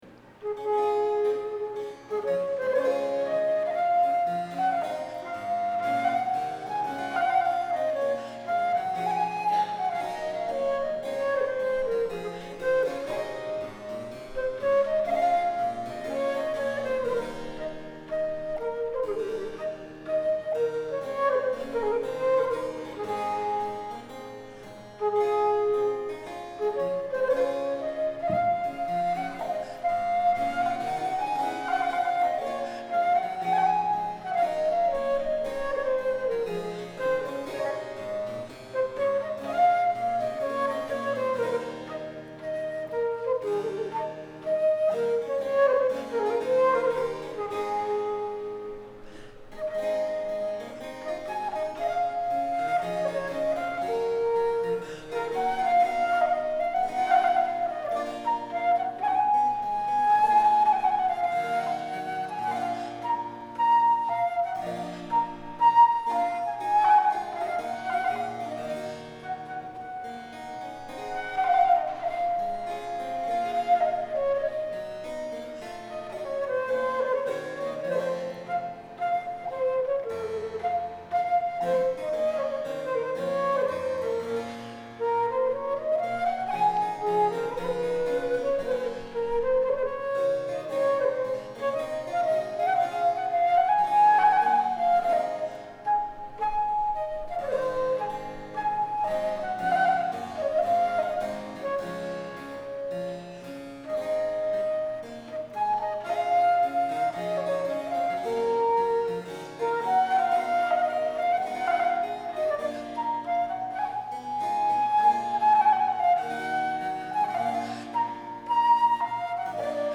Music of the French Baroque
Chicago Cultural Center
Allemande